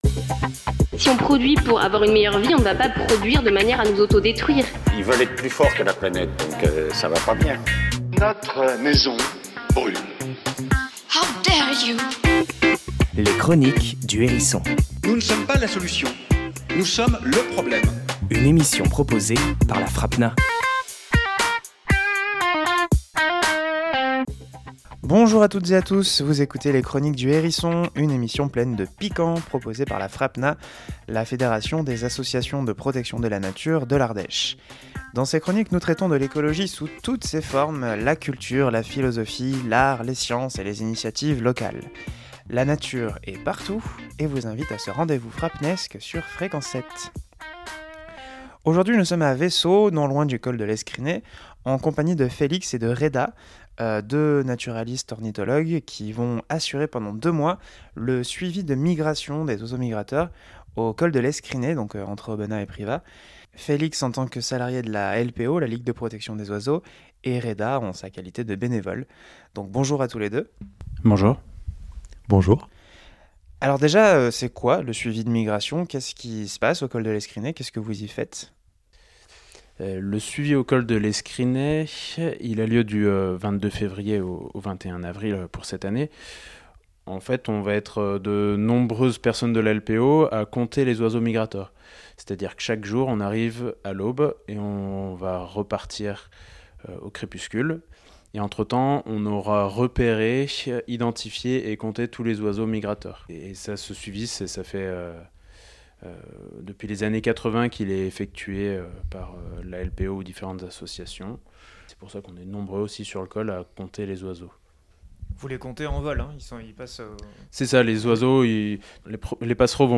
Alessandro Pignocchi, auteur d'essais et de bande-dessinées dans lesquelles se mélangent philosophie de la pensée, politique des luttes et humour anar', nous parle au micro du hérisson de transformer nos territoires en prenant en compte les intérêts de tout le monde, y compris, bien sûr, des vivants non-humains.